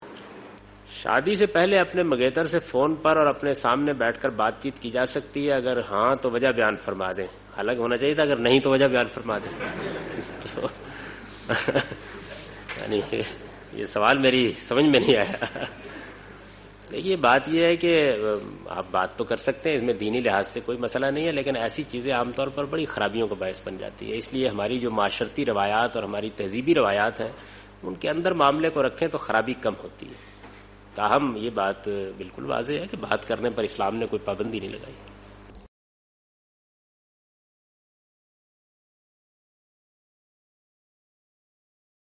Javed Ahmad Ghamidi responds to the question 'Can parents be disobeyed in matters of marriage and education'?